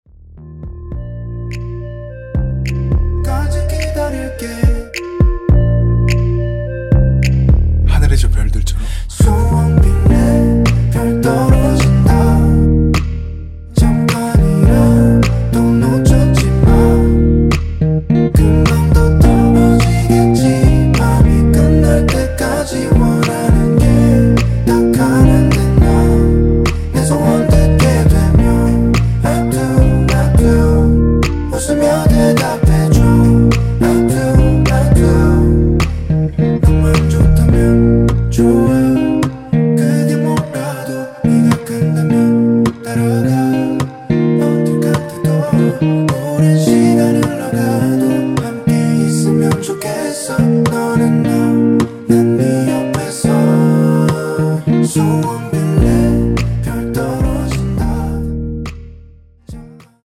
원키에서(-1)내린 멜로디와 코러스 포함된 MR입니다.(미리듣기 확인)
Bb
앞부분30초, 뒷부분30초씩 편집해서 올려 드리고 있습니다.
곡명 옆 (-1)은 반음 내림, (+1)은 반음 올림 입니다.
(멜로디 MR)은 가이드 멜로디가 포함된 MR 입니다.